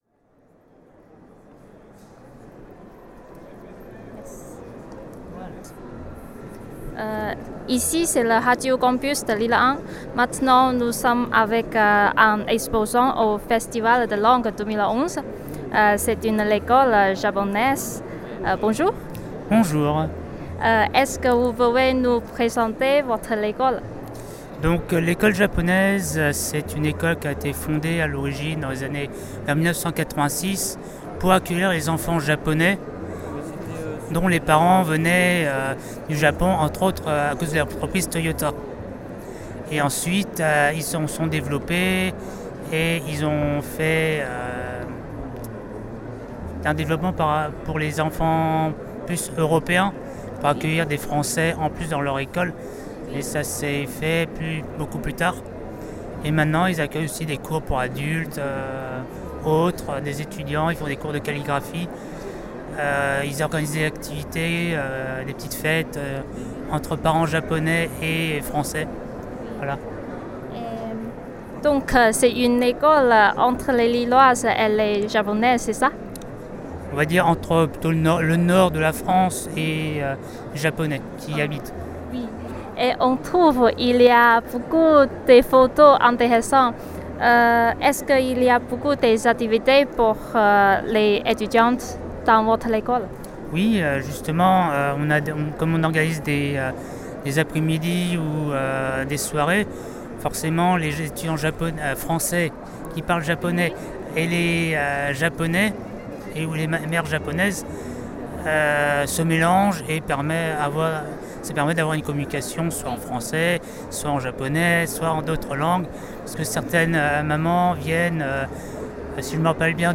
Cette 7ème édition du festival des langues a eu lieu les 8 et 9 avril 2011 à la Chambre de Commerce et d'Industrie Grand Lille
L'équipe était constituée d'étudiants chinois de Lille 1